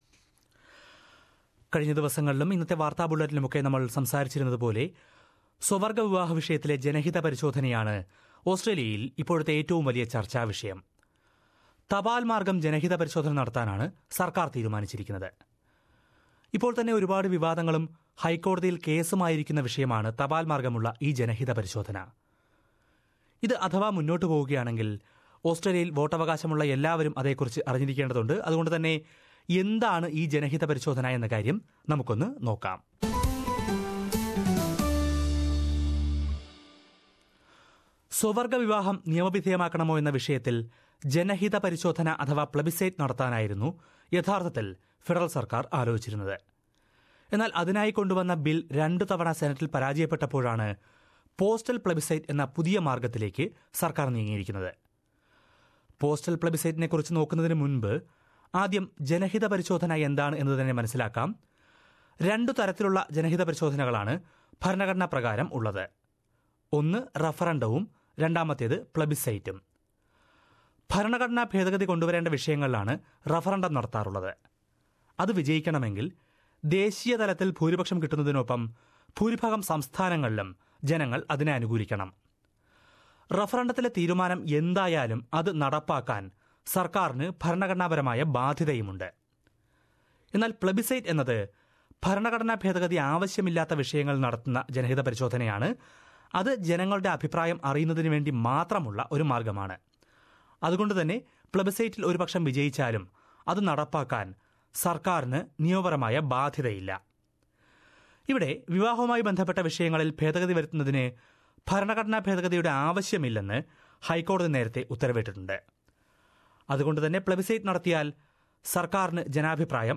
സ്വവർഗ്ഗ വിവാഹം നിയമവിധേയമാക്കണമോ എന്ന വിഷയത്തിൽ തപാൽ മാർഗ്ഗമുള്ള ജനഹിത പരിശോധന നടത്താനുള്ള തീരുമാനവുമായി മുന്നോട്ടുപോകുകയാണ് സർക്കാർ. എന്താണ് പോസ്റ്റൽ പ്ലെബിസൈറ്റെന്നും, ഇതിന് മറ്റു ജനഹിത പരിശോധനകളുമായുള്ള വ്യത്യാസമെന്തെന്നും വിശദീകരിക്കുന്ന റിപ്പോർട്ട് കേൾക്കാം...